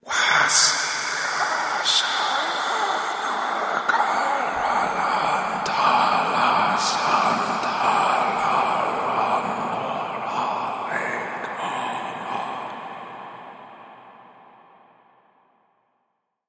Звуки пугающие
Звук голоса дьявола читающего заклинание